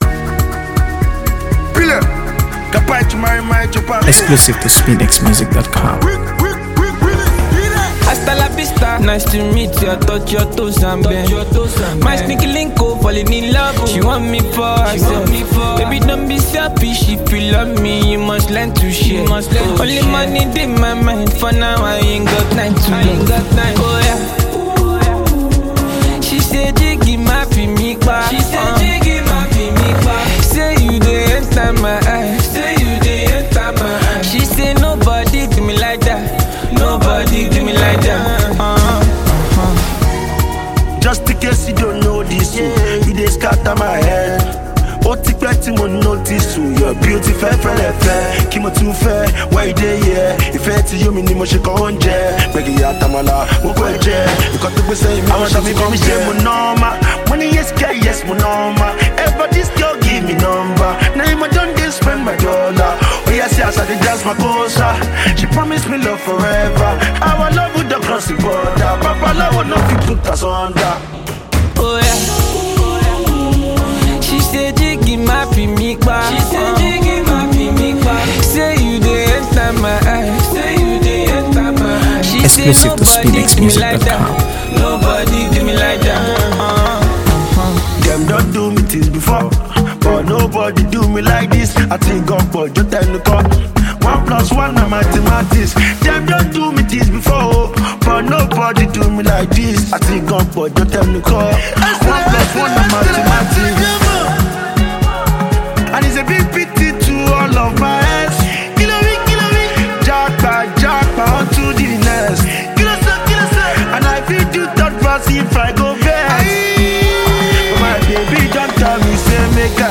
AfroBeats | AfroBeats songs
Afro-pop anthem